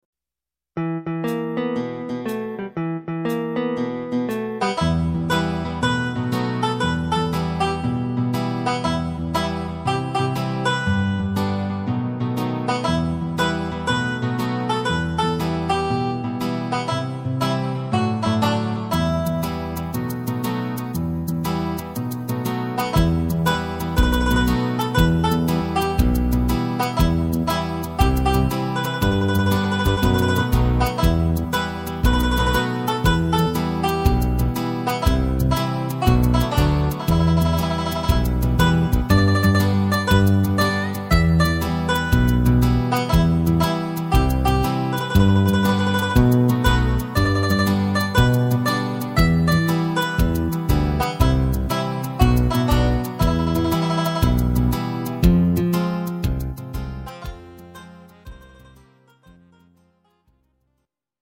instrumental Banjo